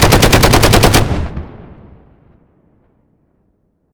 machineout.ogg